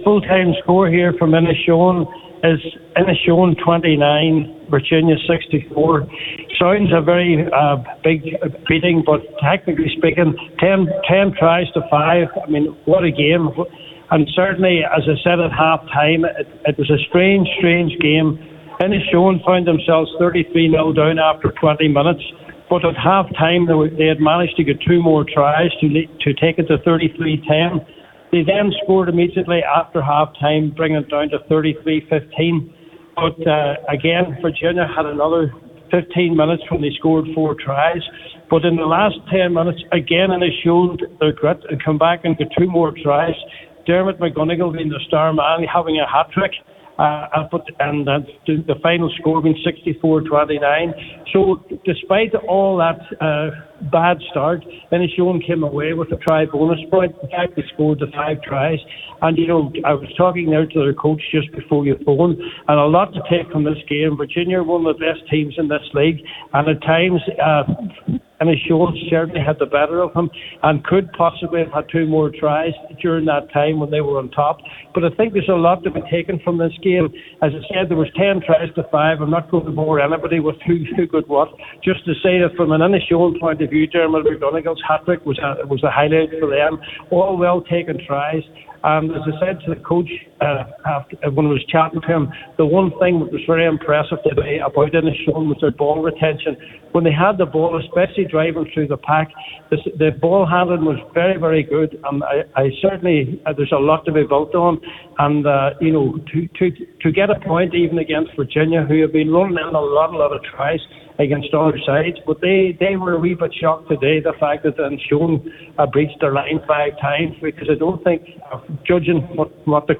With the full time report